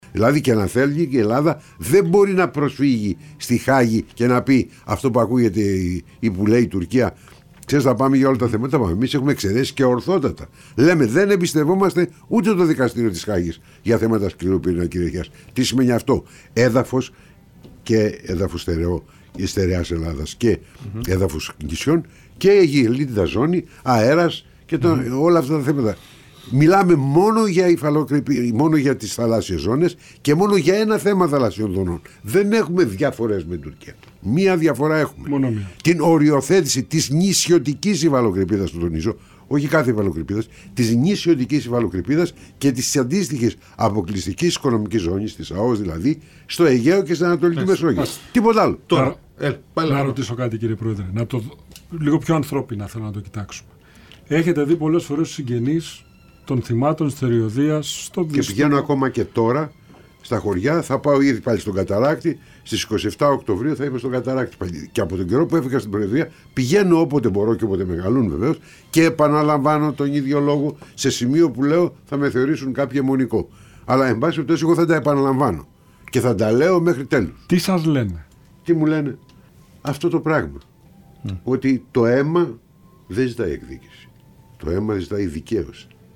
Προκόπης Παυλόπουλος στον Realfm 97,8: «Το αίμα δεν ζητάει εκδίκηση, ζητάει δικαίωση» - Τι είπε για τα ελληνοτουρκικά